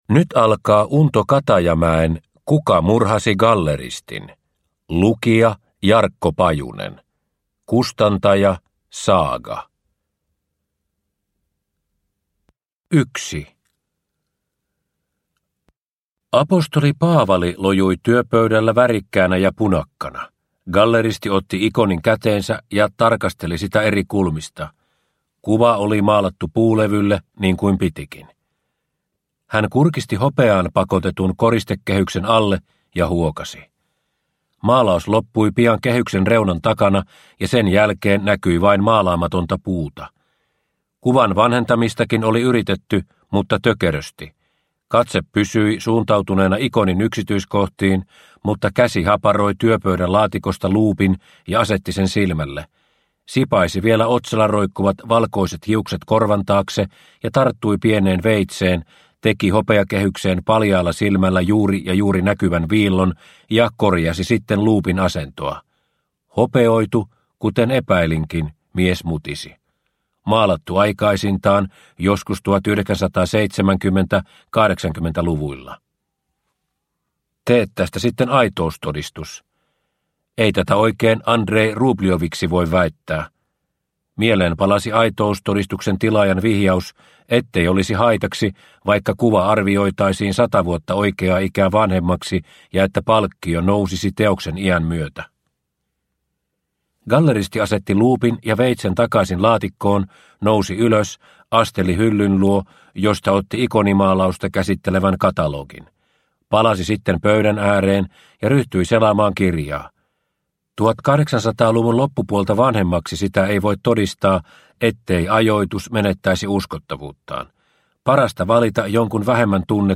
/ Ljudbok